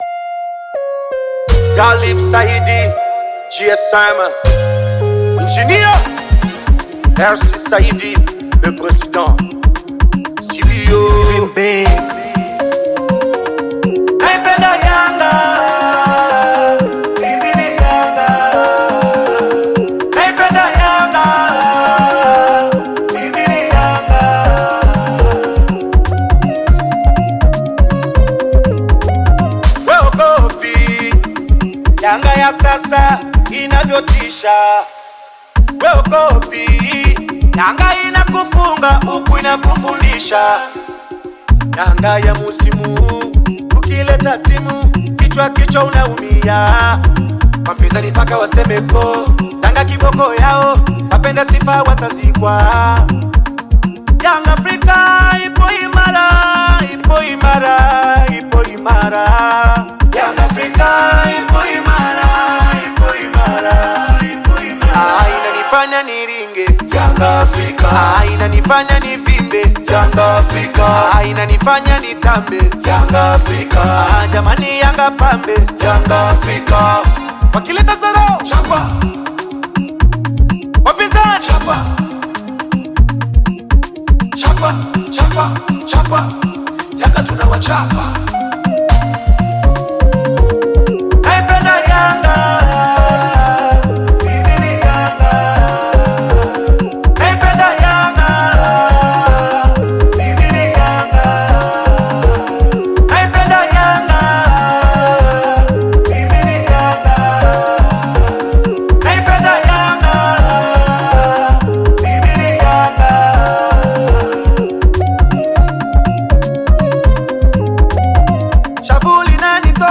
Bongo Flava music track
Tanzanian artist, singer, and songwriter
This catchy new song